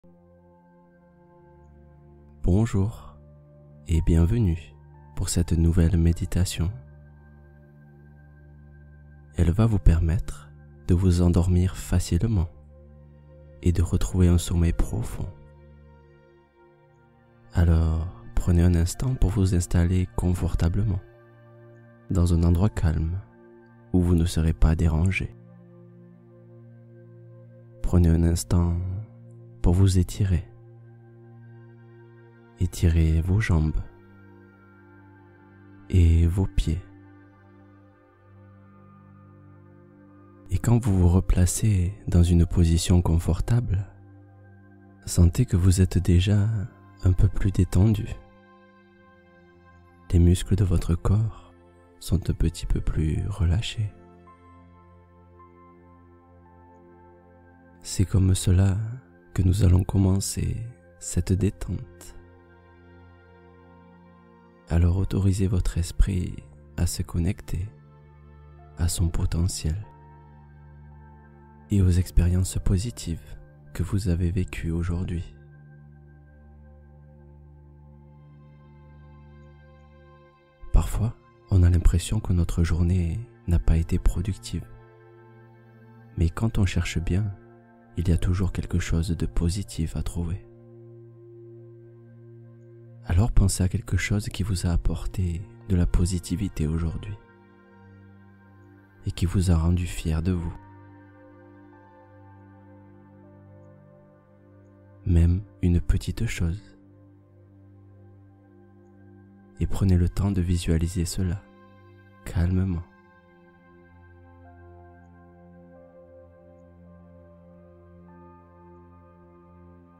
Visualisation guidée : exercice puissant pour amplifier la confiance